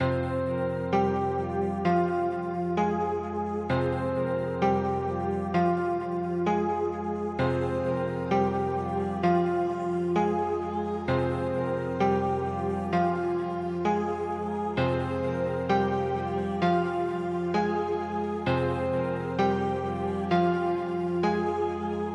恐怖类型的旋律
Tag: 130 bpm Trap Loops Piano Loops 3.73 MB wav Key : A